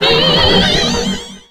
Cri de Magirêve dans Pokémon X et Y.